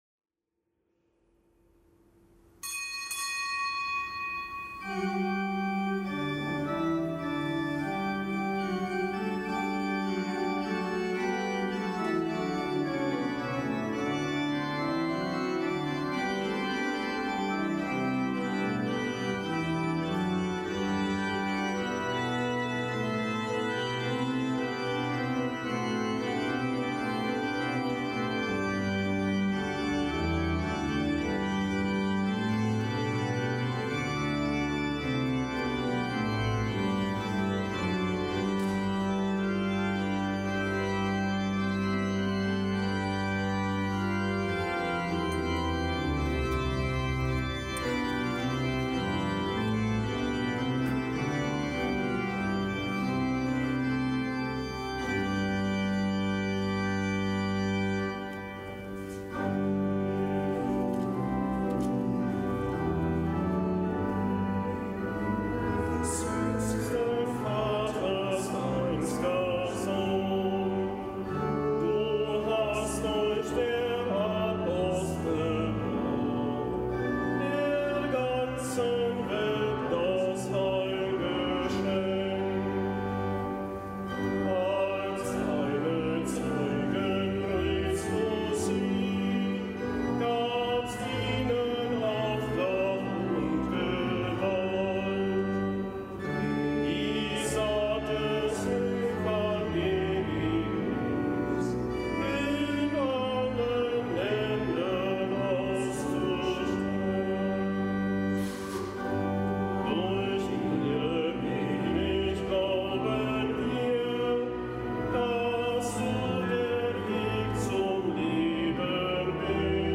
Kapitelsmesse aus dem Kölner Dom am Gedenktag des Heiligen Barnabas, Apostel.